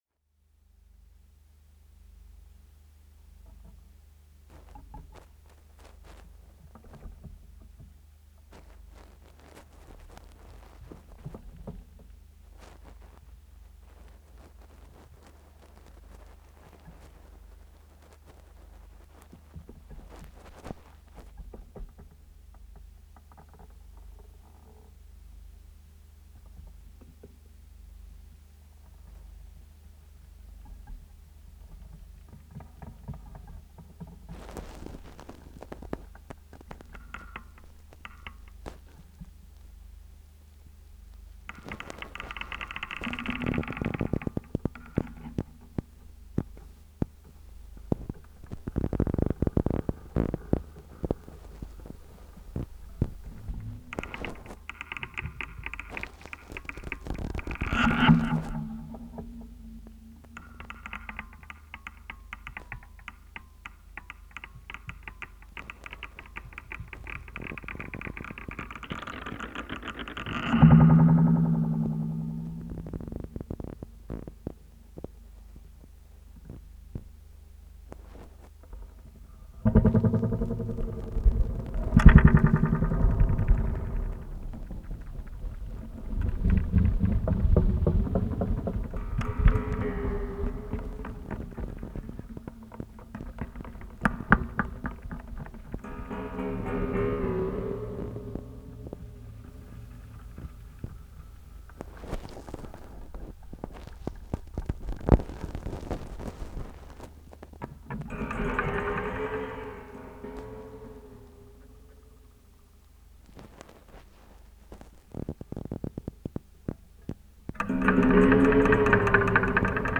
improvisation – abstract – experimental
Live performance